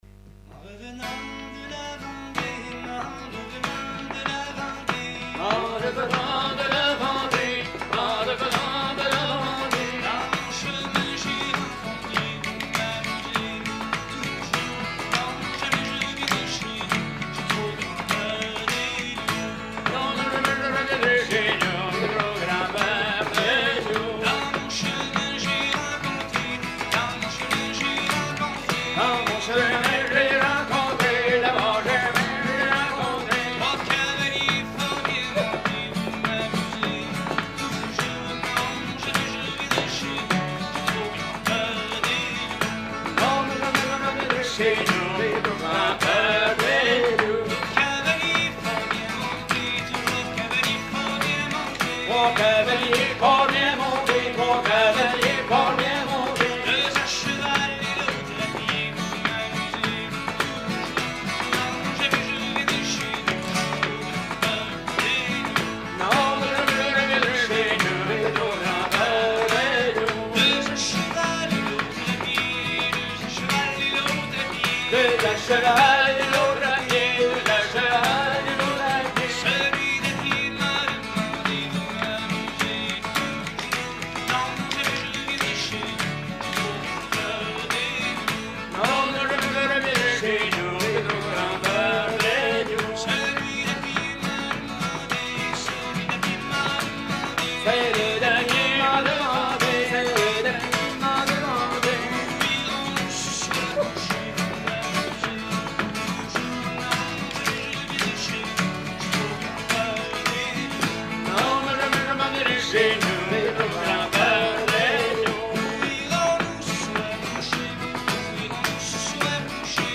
Genre laisse
Concert à la ferme du Vasais
Pièce musicale inédite